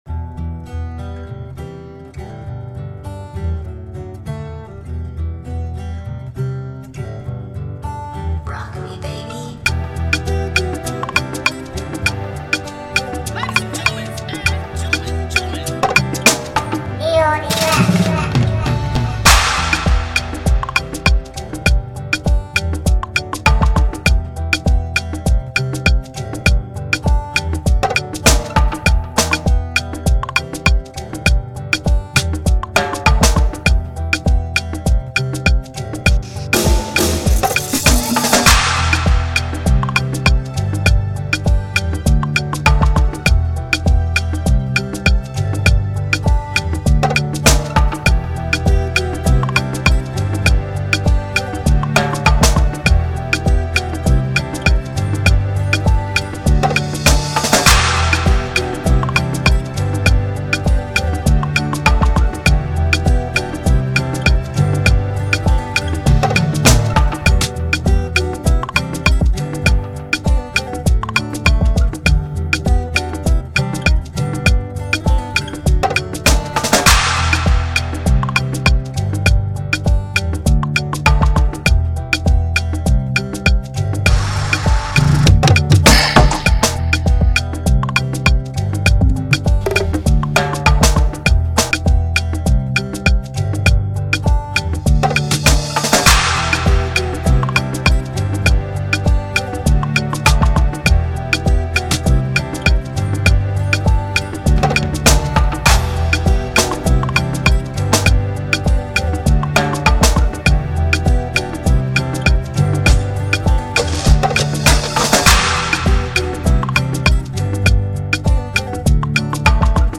’ where Afro Jazz meets Afro Pop